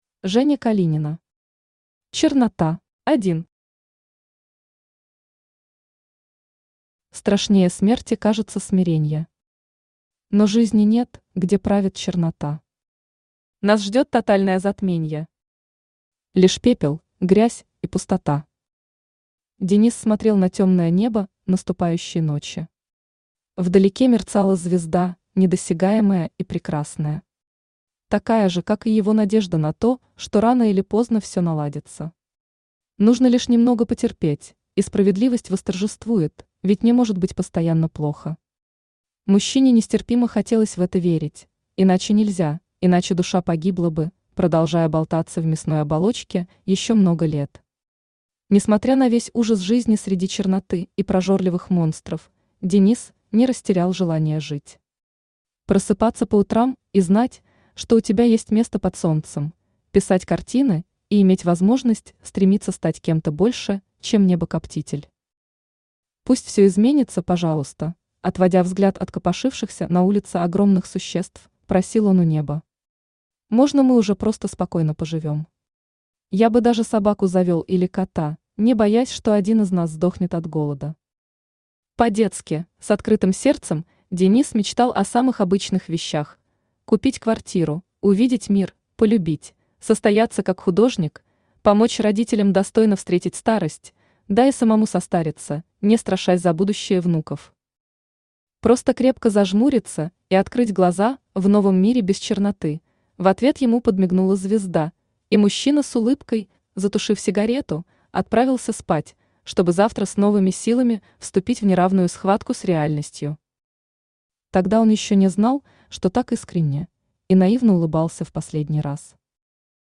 Аудиокнига Чернота | Библиотека аудиокниг
Aудиокнига Чернота Автор Женя Калинина Читает аудиокнигу Авточтец ЛитРес.